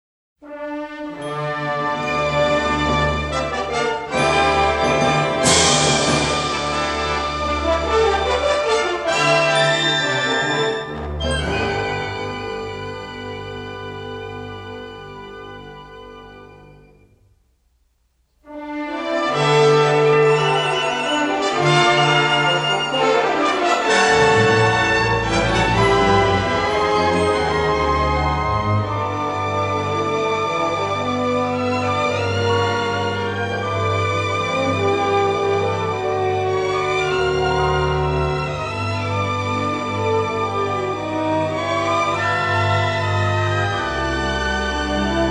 a deeply melodic, romantic and sophisticated score